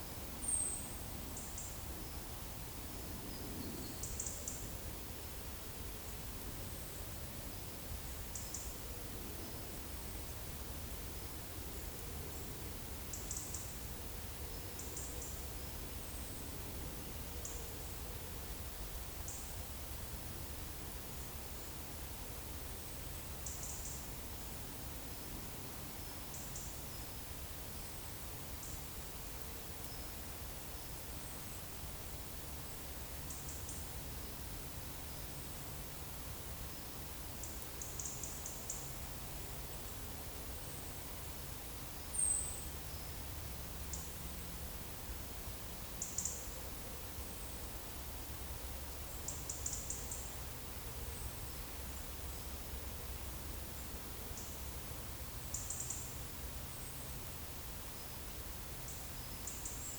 Monitor PAM
Erithacus rubecula
Regulus regulus
Coccothraustes coccothraustes
Columba palumbus
Turdus merula